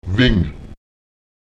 Lautsprecher wen [wEN] laufen